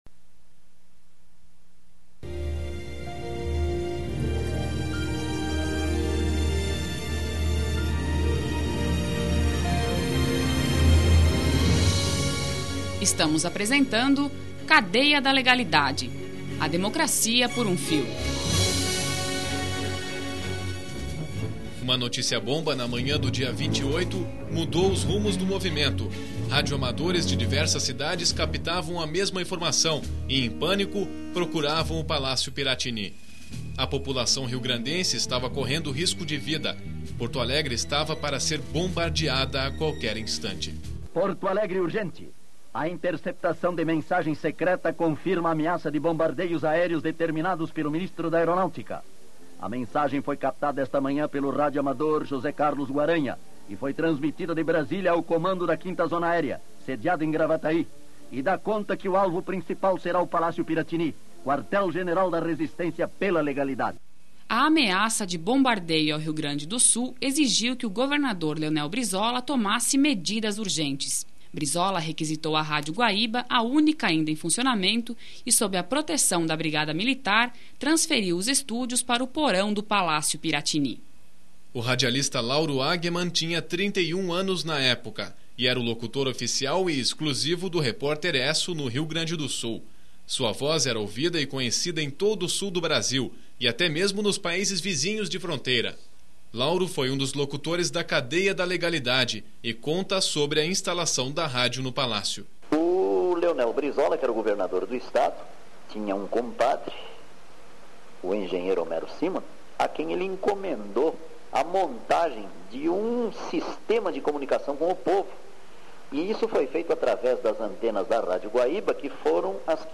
radiodocumentário